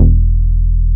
R MOOG E2P.wav